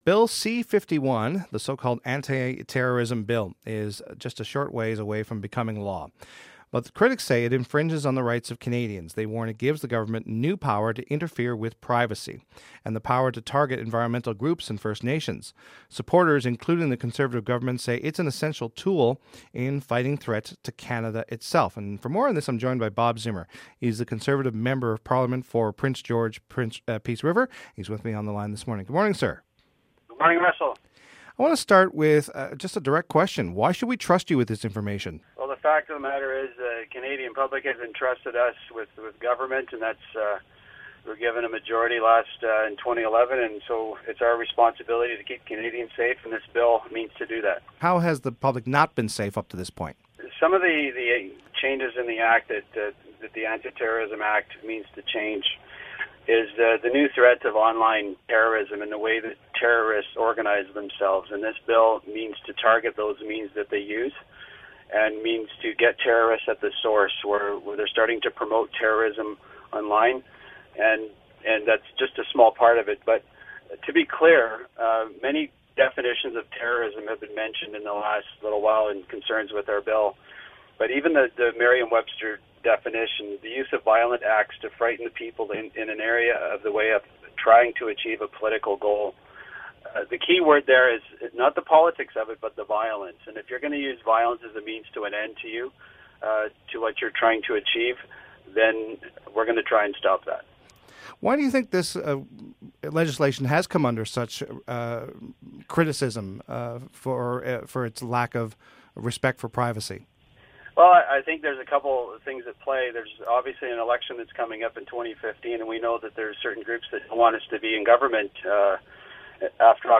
Nut Prince George - Peace River MP Bob Zimmer says it is necessary to keep Canadians safe. Listen to the interview.